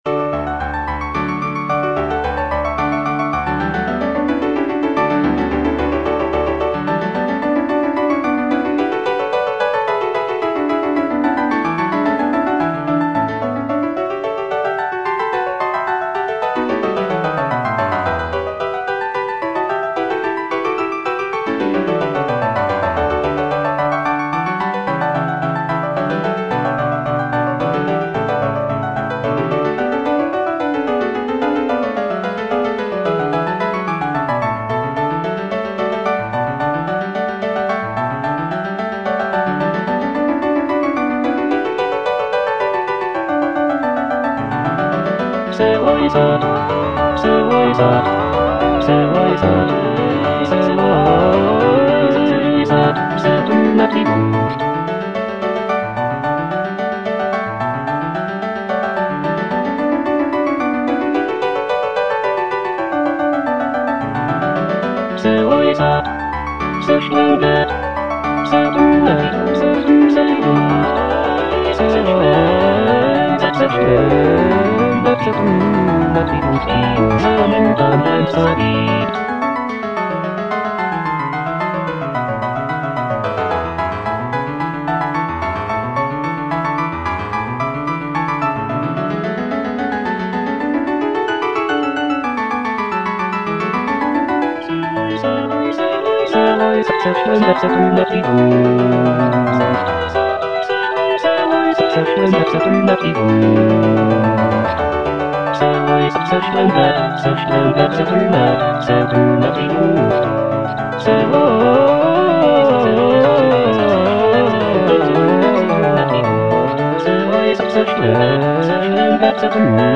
Choralplayer playing Cantata
The cantata features a dialogue between the wind god Aeolus and the river god Alpheus, celebrating the prince's virtues and rulership. The music is lively and celebratory, with intricate counterpoint and virtuosic vocal lines.